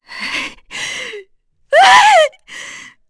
FreyB-Vox_Sad1.wav